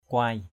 /kʊoɪ/ 1.